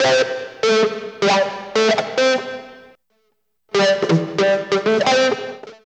62 GUIT 2 -R.wav